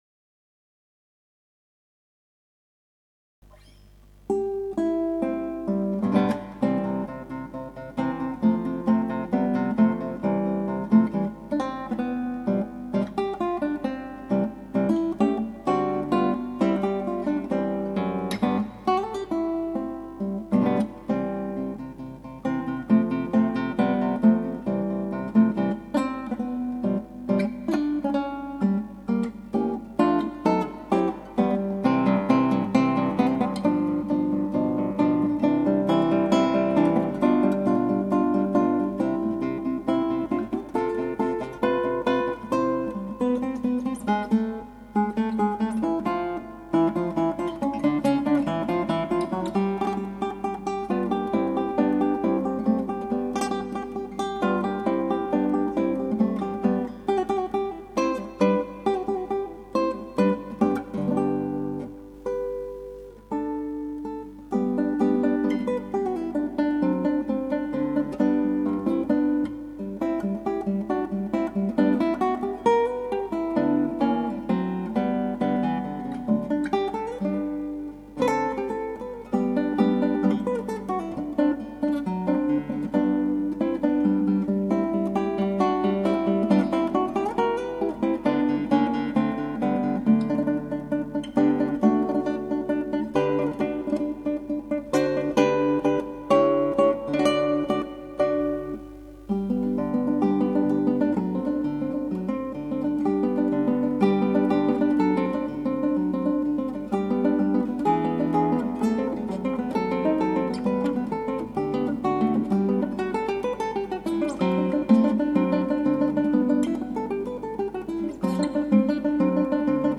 クラシックギター 「ソナチネじゃね・・・」 -「Soante Op15-2」 Sor-
ギターの自演をストリーミングで提供
まあ、まったりとした曲でなごみます。こういうシンプルな曲は練習不足がバレますね・・・。